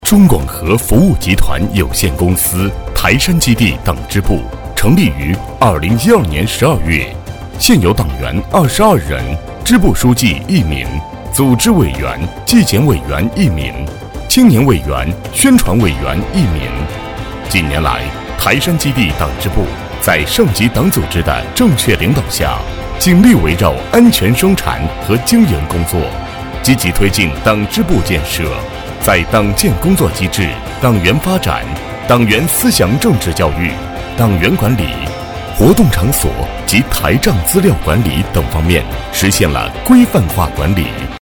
磁性男音，中年声线。中规中矩、激情有力。